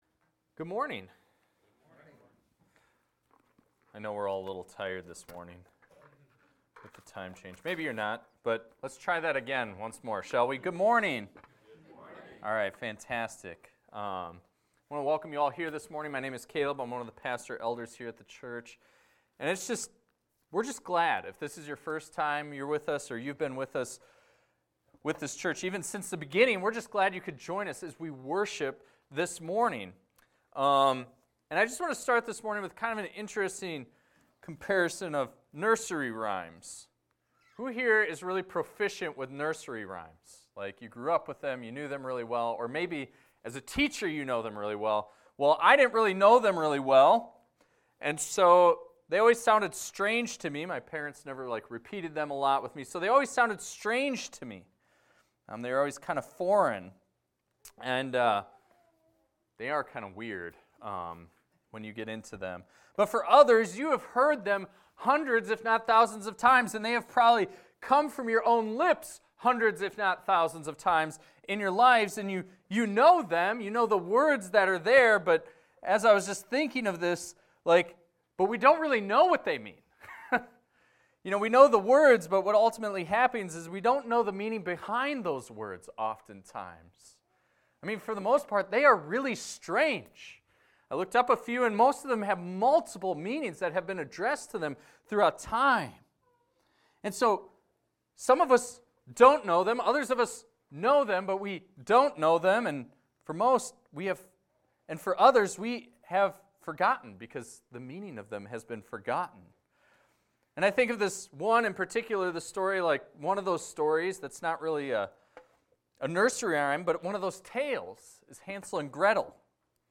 This is a recording of a sermon titled, "It Is Finished."